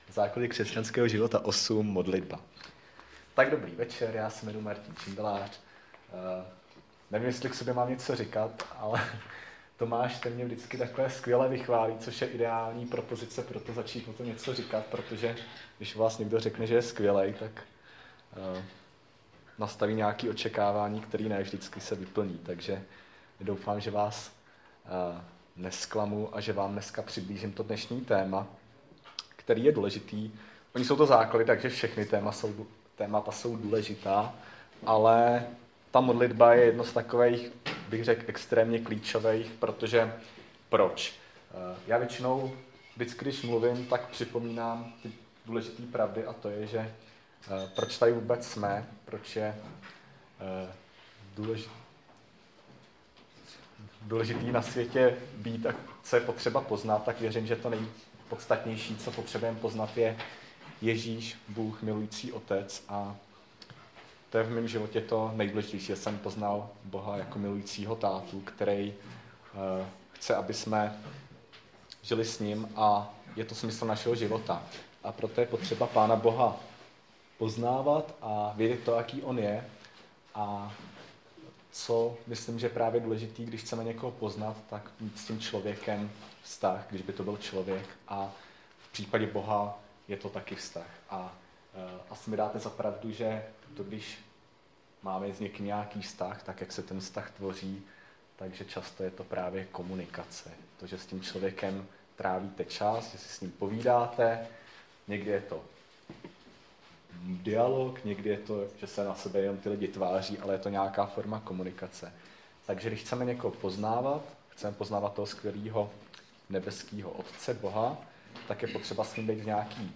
Nahrávka z desáté lekce Základů křesťanského života z 12. března 2020.